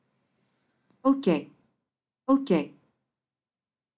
le mot ok prononcé dans une langue à deviner